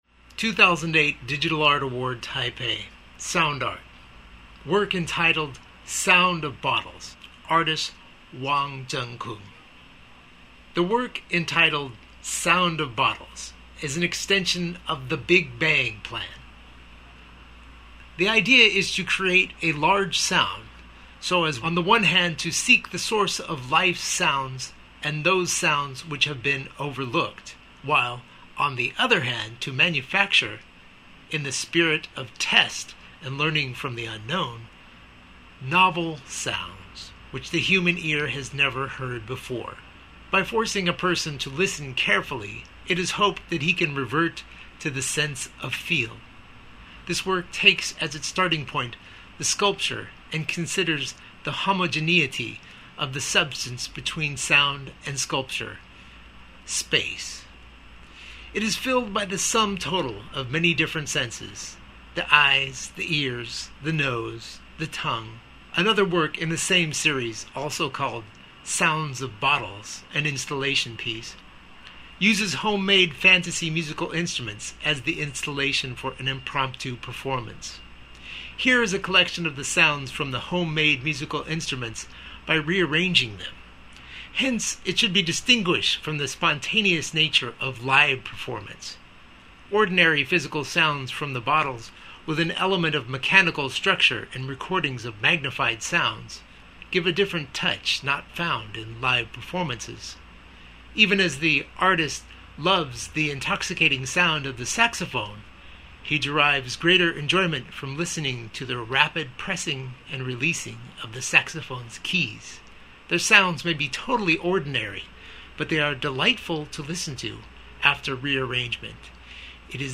MP3 Guided Tour